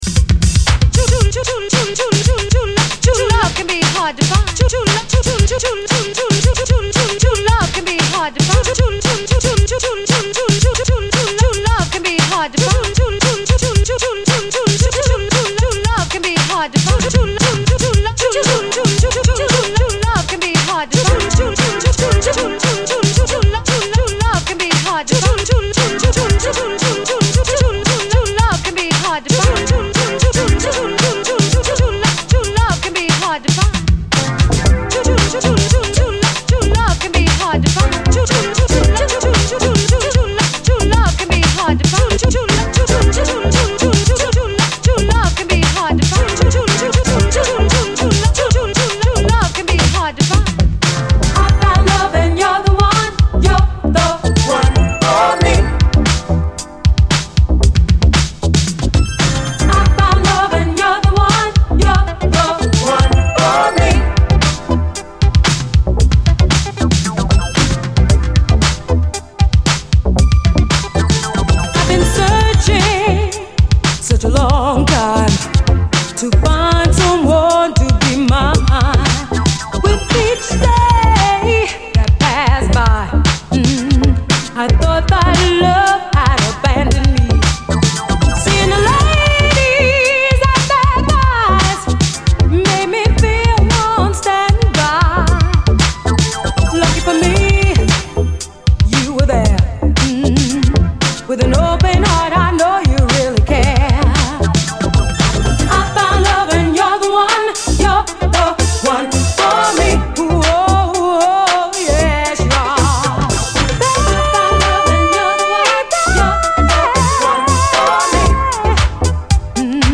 DISCO
NY産80’Sメロウ・シンセ・ブギー！
しっとりとしたシンセがアーバン。